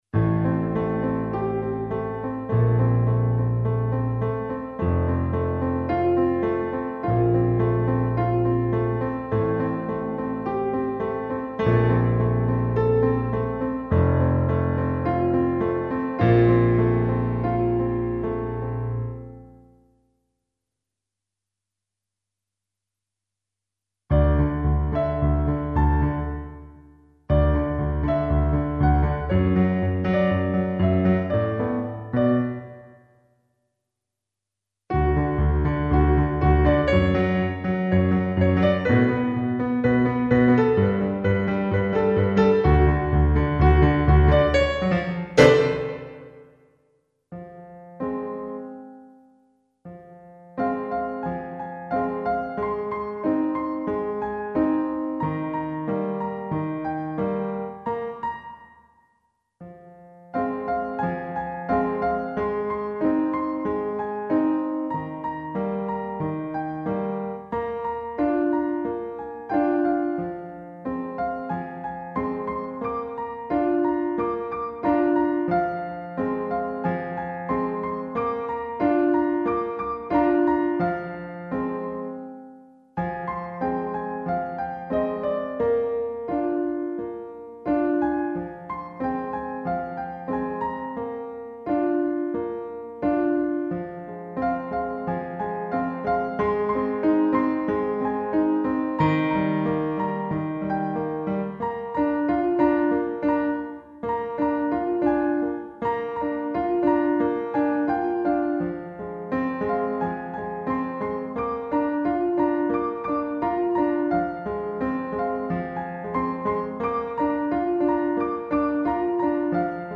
Zu aller erst mal Geklimper vom Keyboard.